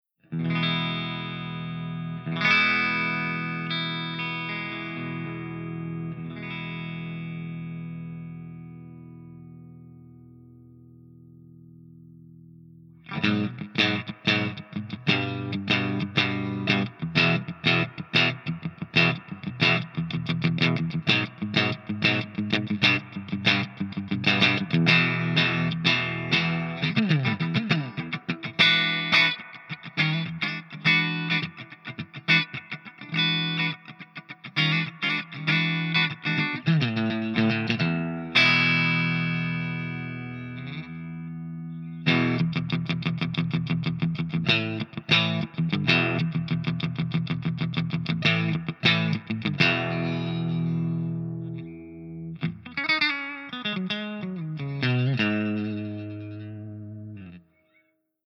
093_HIWATT_STANDARDCLEAN_GB_SC.mp3